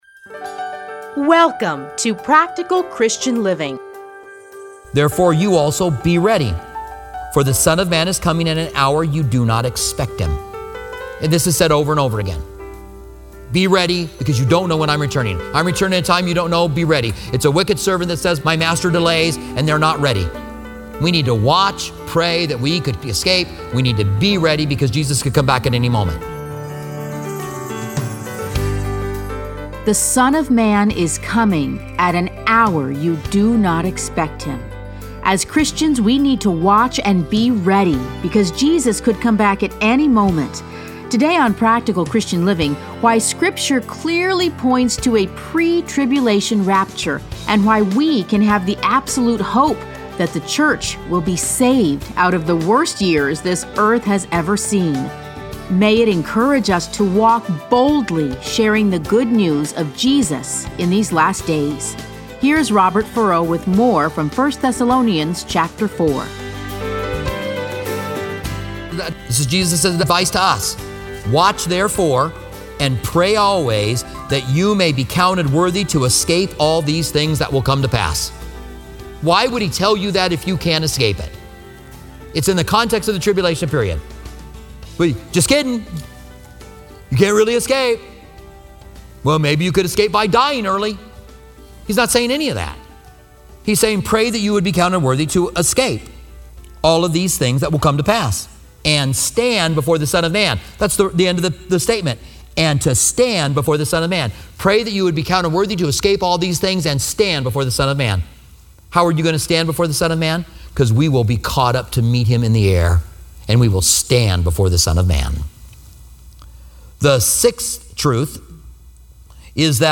Listen to a teaching from 1 Thessalonians 4:13-18.